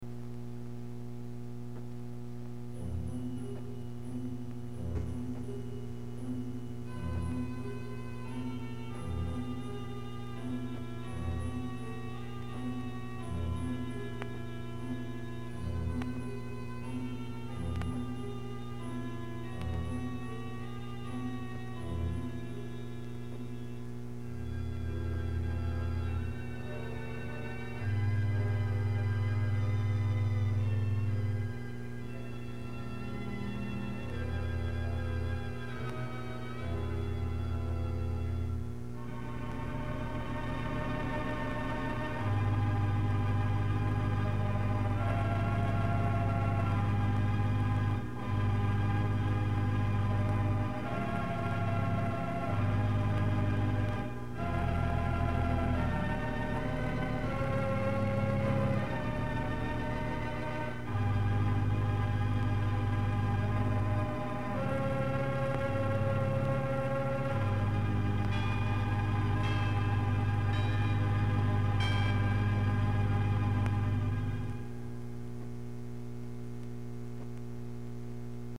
Pipe Organ Music